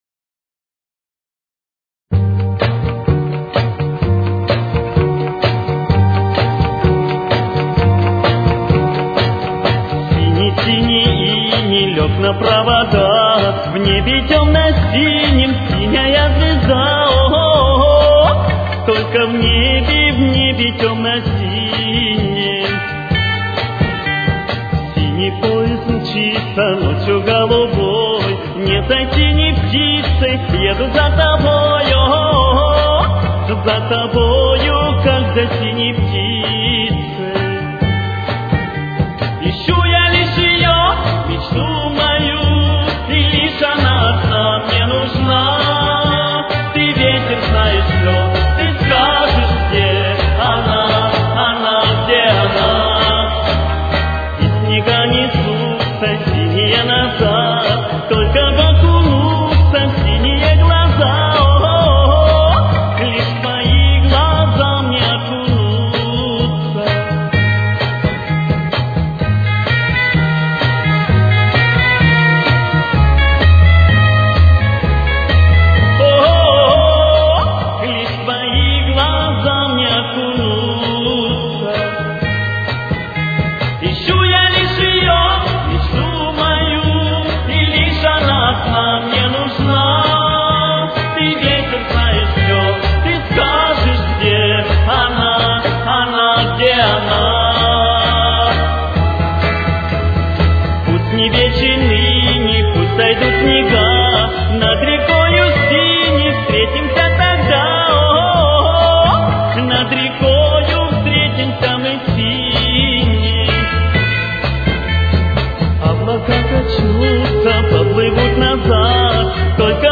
Тональность: Соль минор. Темп: 133.